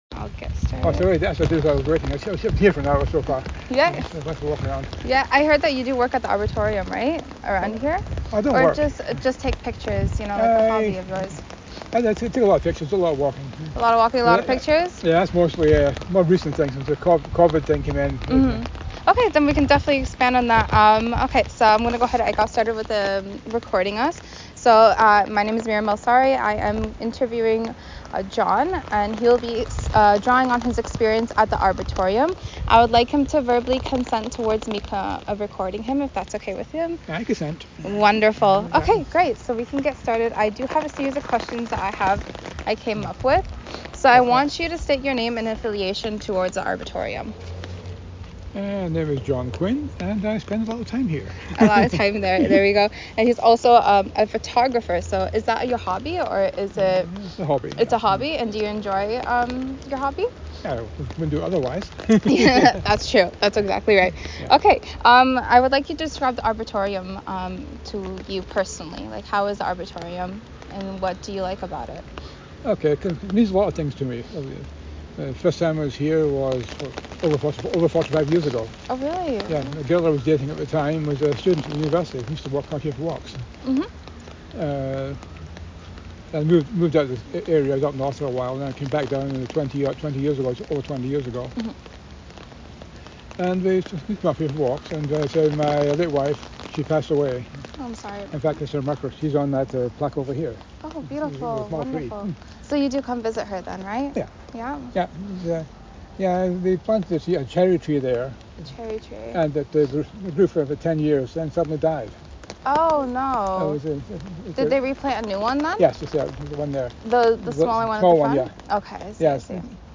memorial_grove_interview.wav